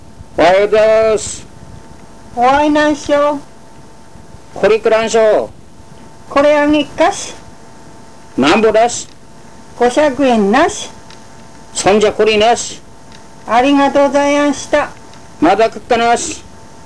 この町のことばをお聴きいただけます
買物の会話 RealAudio